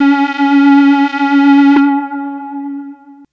Vibrato Pad.wav